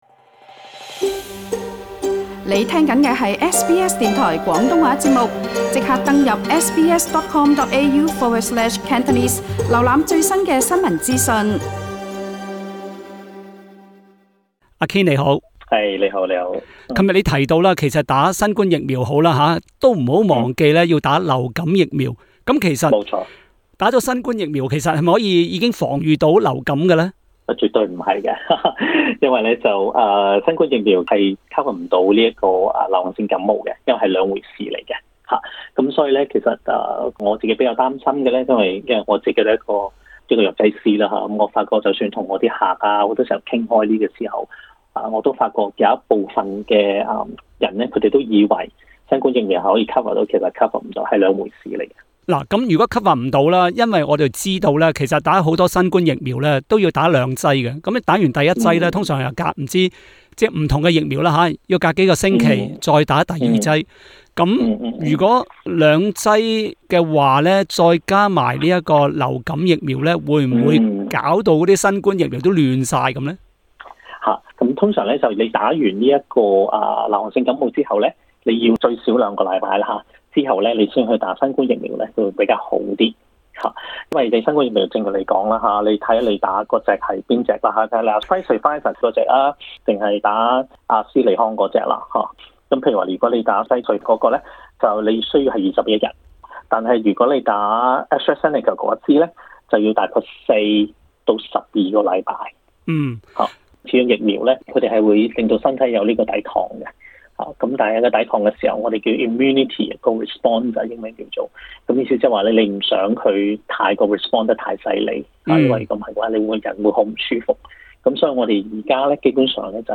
的访问